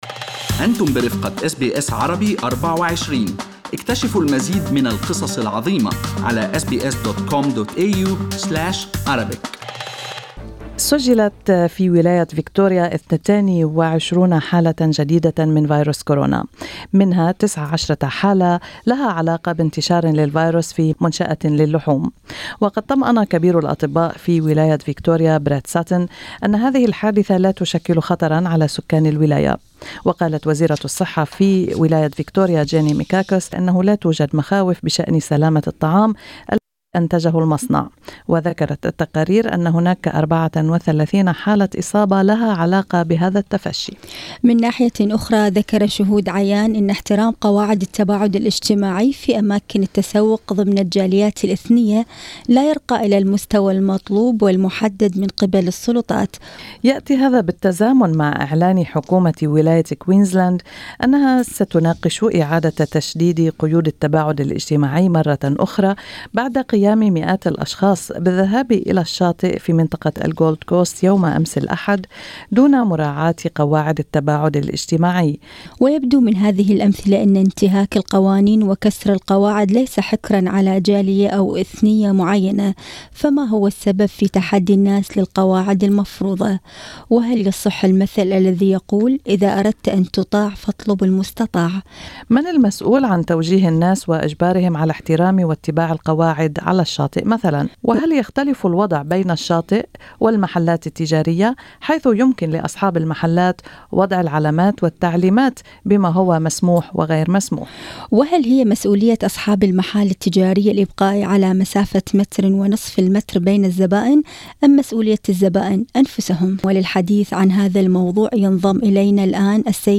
استمعوا إلى اللقاء كاملا تحت الشريط الصوتي في أعلى الصفحة.